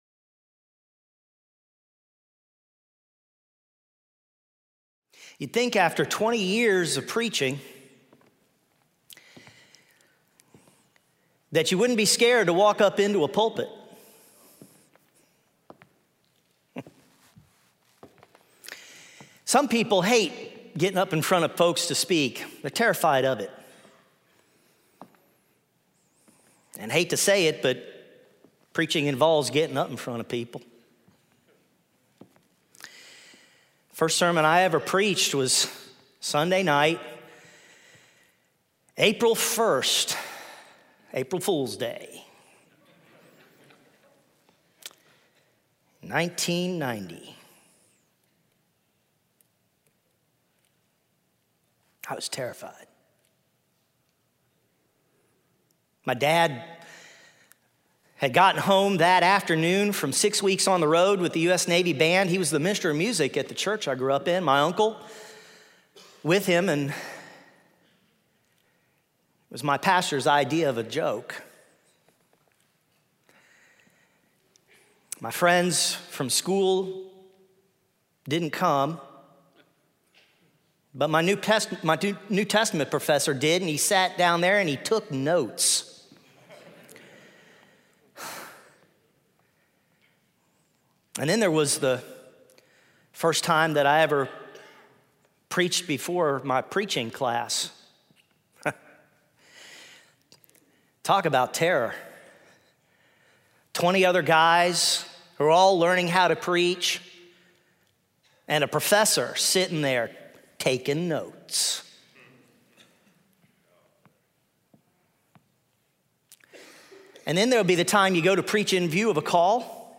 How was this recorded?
speaking on II Corinthians 4 in SWBTS Chapel